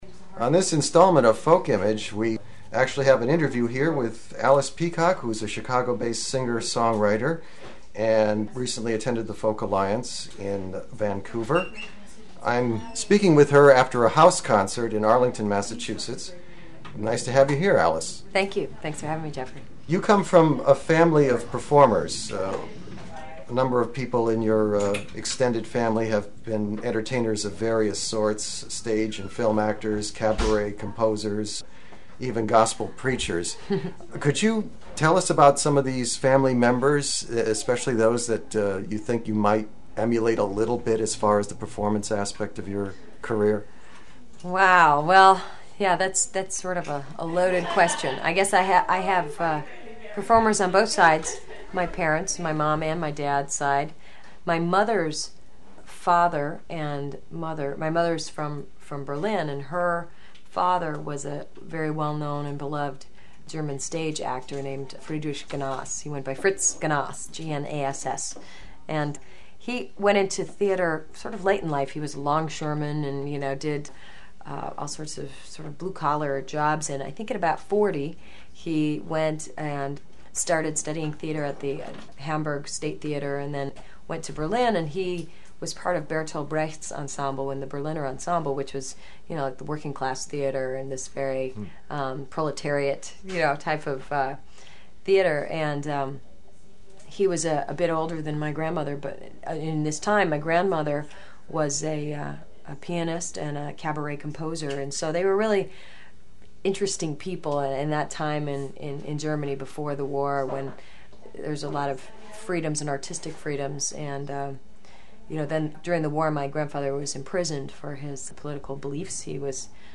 March 2001 Interview